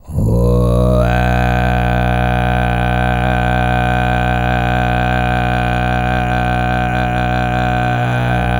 TUV5 DRONE03.wav